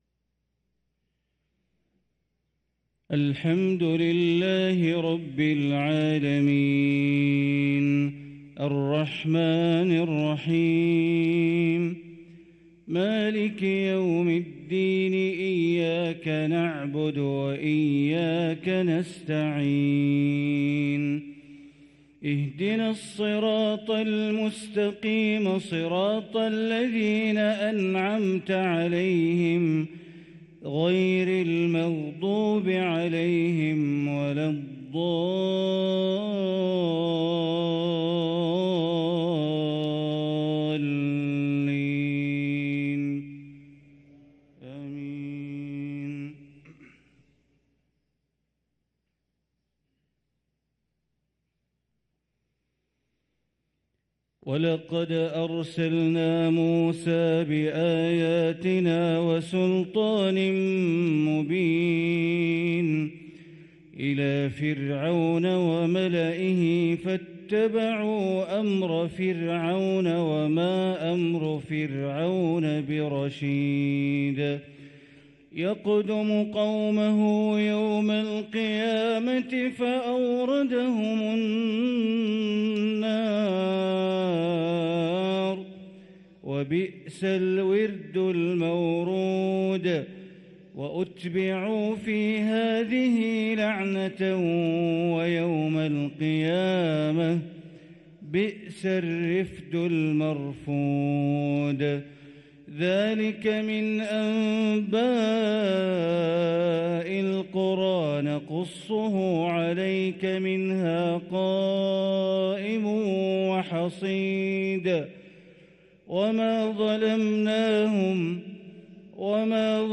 صلاة الفجر للقارئ بندر بليلة 17 صفر 1445 هـ